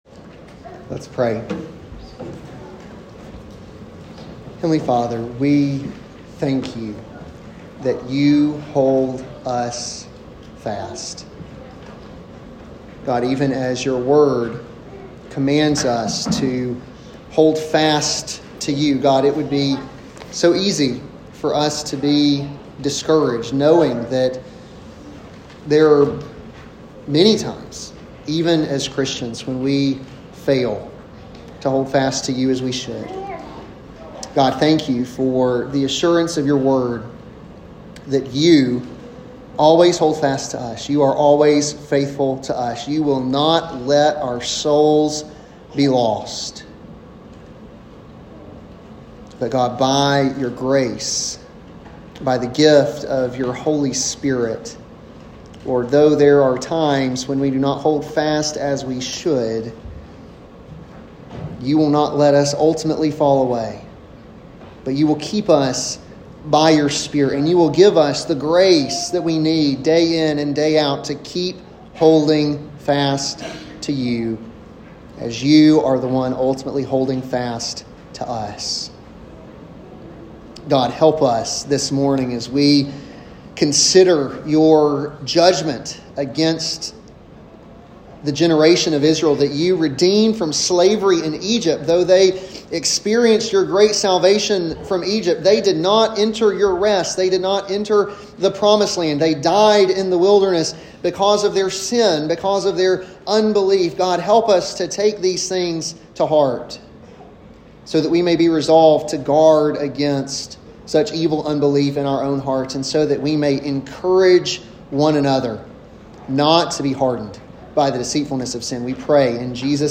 an expository sermon on Hebrews 3:7-19